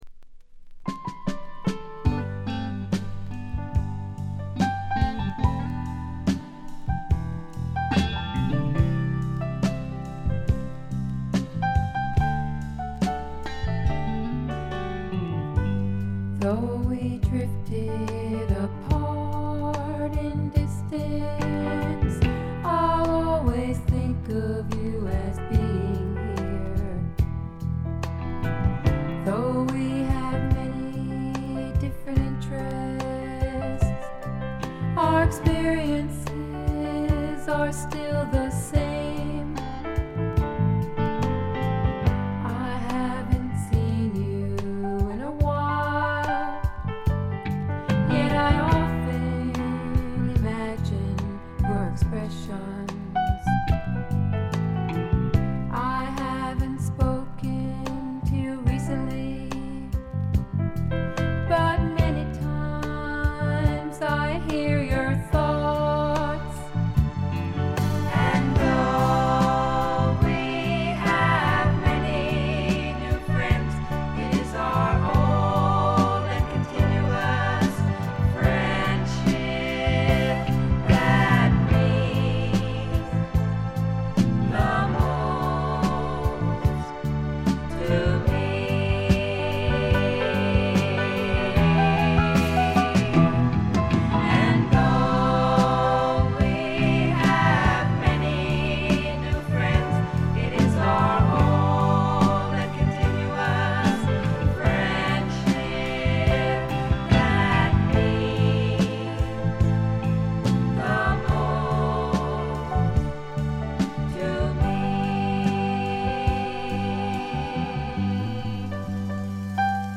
少し舌足らずな声で歌う楚々とした魅力が最高です。
基本は控えめなバックが付くフォークロック。
試聴曲は現品からの取り込み音源です。